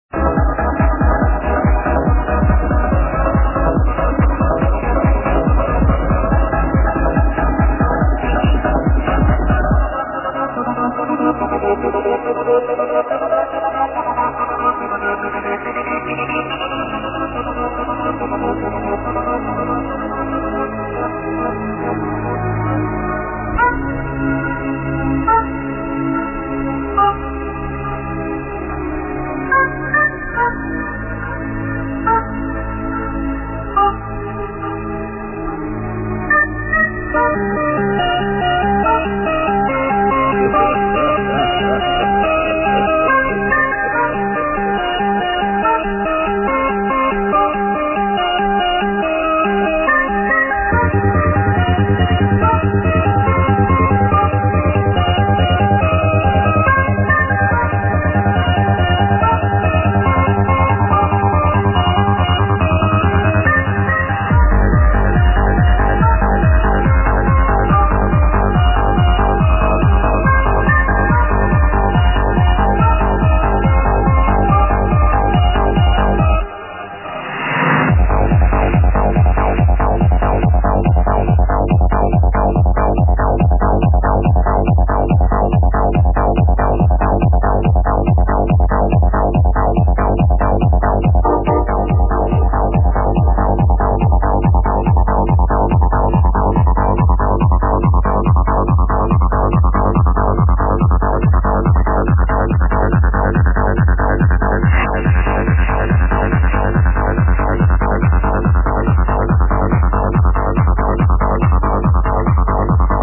this is at all a almost 10 minutes long song from i think 97. played in german radioshwo..simply amazing this one..but what is the name
3 seconds clip lol how we meant to tell of that lots of tunes have a gated synth like that